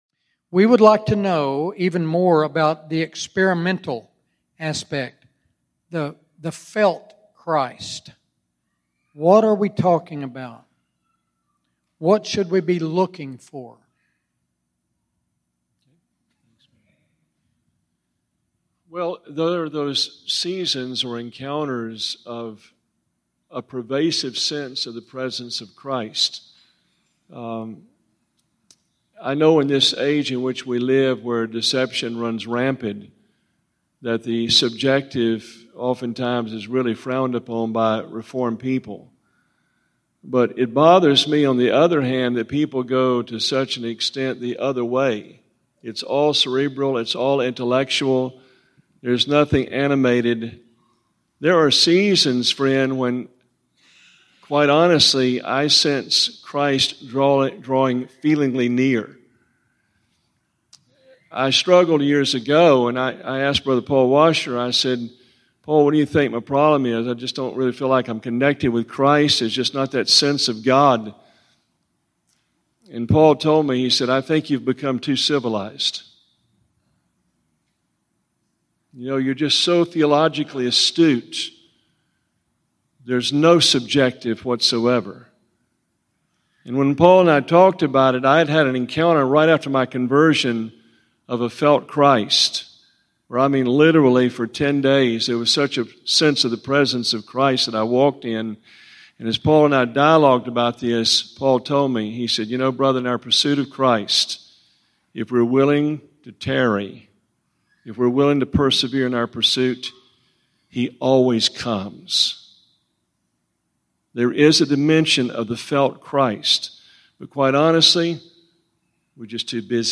What is Experiential Christianity? (Question & Answer)